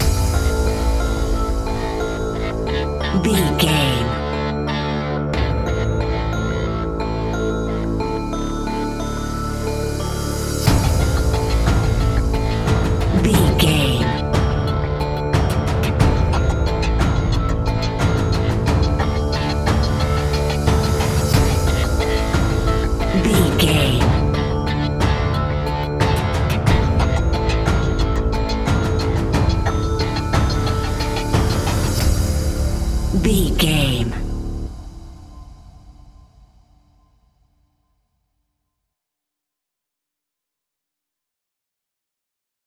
Aeolian/Minor
scary
tension
ominous
dark
haunting
eerie
synthesiser
drums
ticking
electronic music
electronic instrumentals